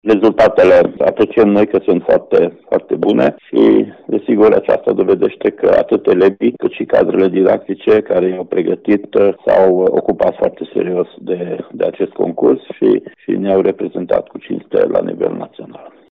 Inspectorul şcolar general al judeţului Mureş, Ştefan Someşan: